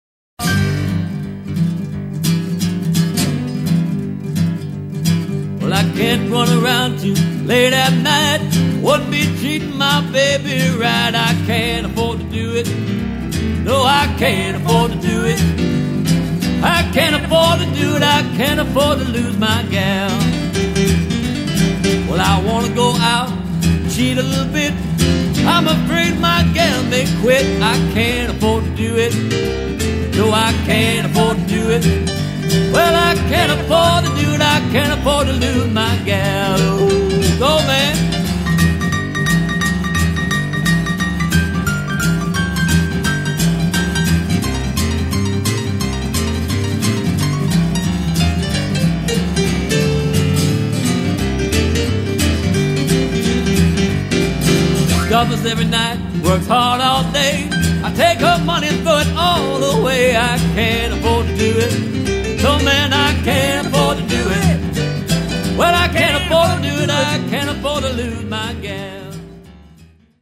Vocal Stylist - Entertainer